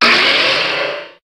Cri de Méga-Ténéfix dans Pokémon HOME.
Cri_0302_Méga_HOME.ogg